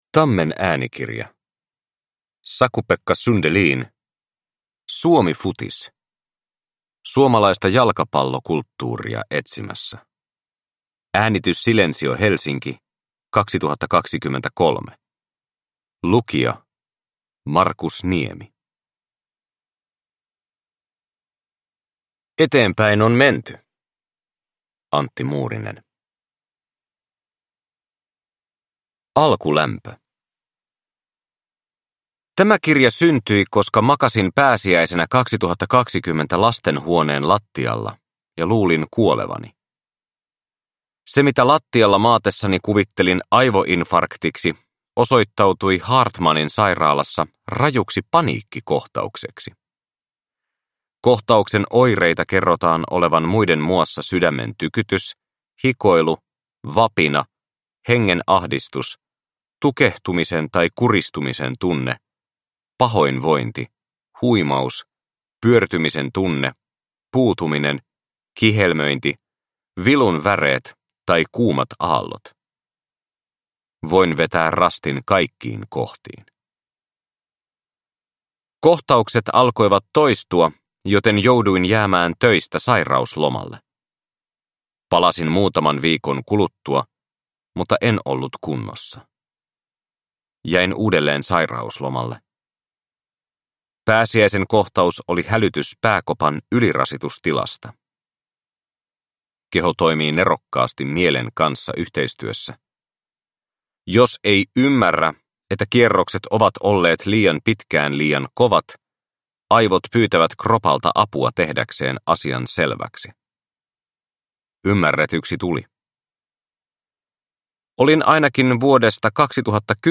Suomifutis – Ljudbok